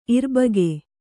♪ irbage